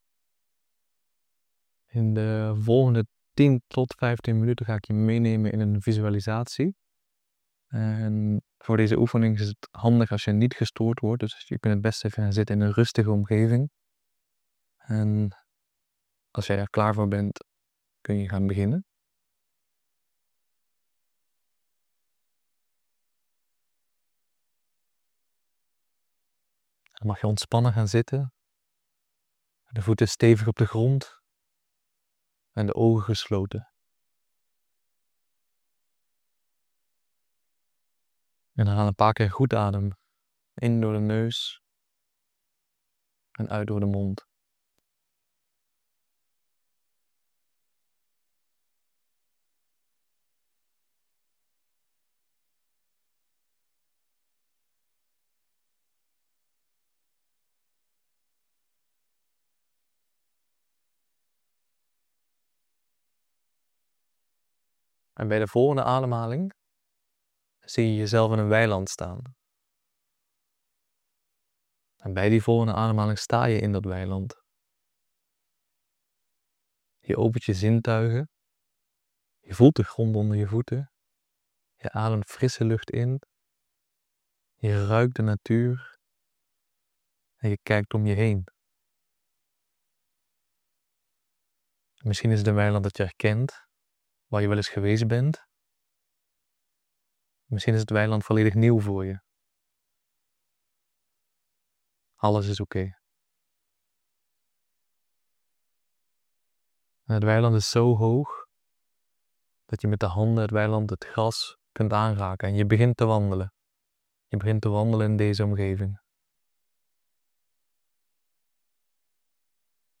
Dit is een geleide meditatie waarbij je contact maakt met je innerlijke kind. Je leert om de natuurlijke kwaliteiten van het kind (en dus jouw eigen kwaliteiten) weer te omarmen, voorbij aan angsten en conditioneringen.